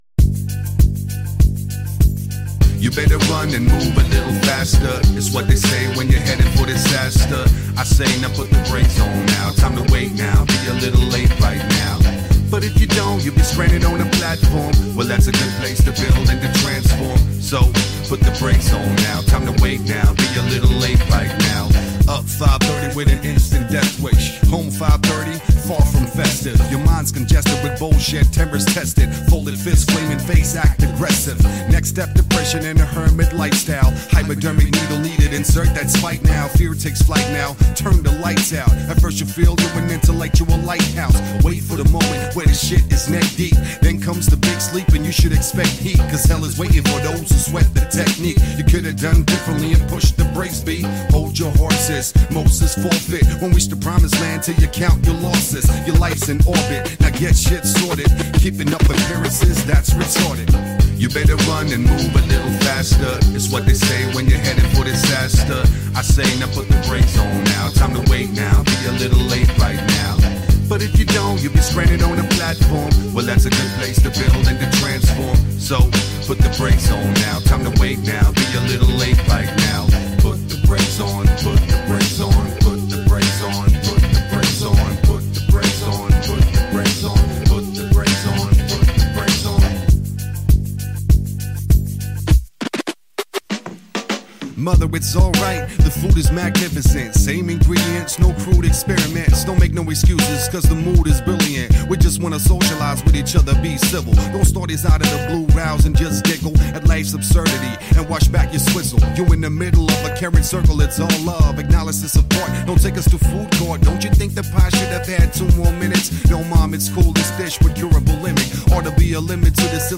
"cool hip-hop" teinté de soul et de groove
un tout nouveau single plein de groove
la voix chaude et suave
un nouvel hymne groove parfait